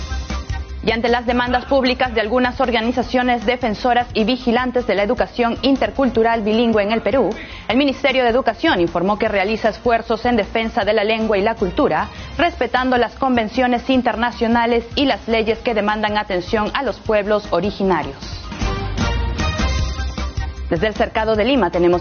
Microinformativo - Exitosa Tv